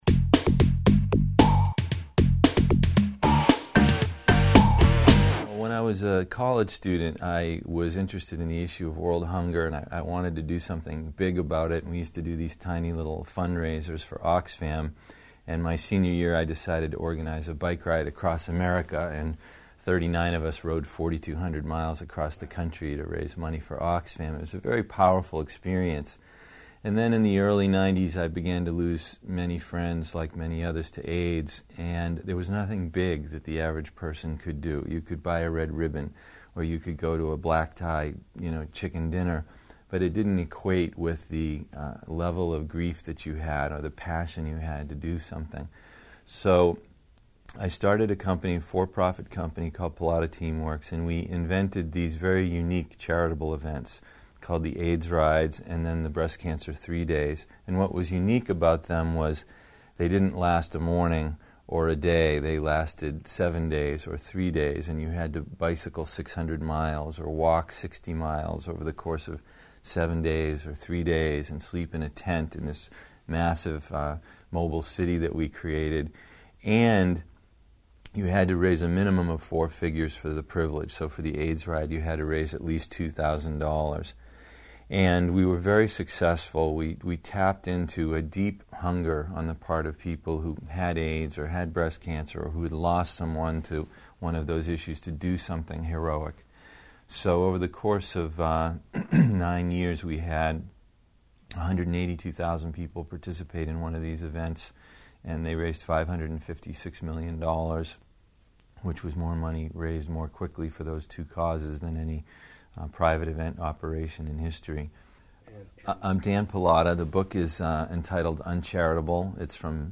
Approximately nine minutes, this interview